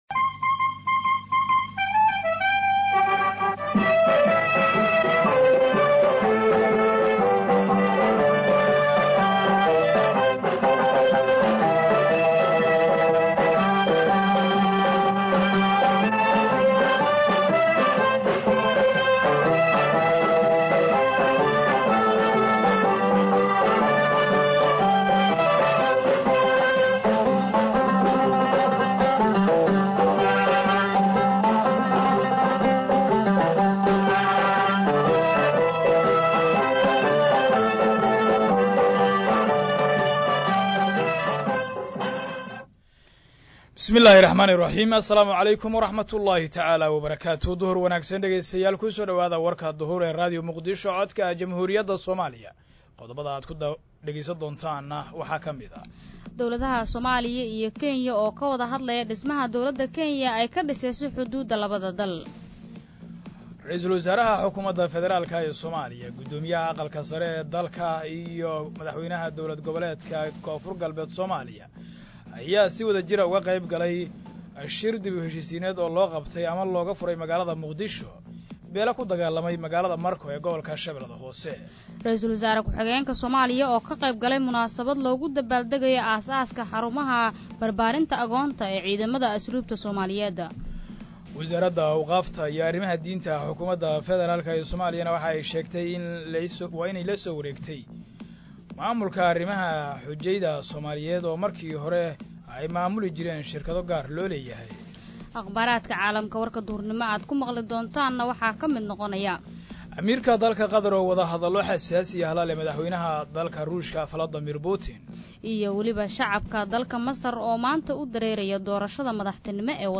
Dhageyso: Warka Duhur ee Radio Muqdisho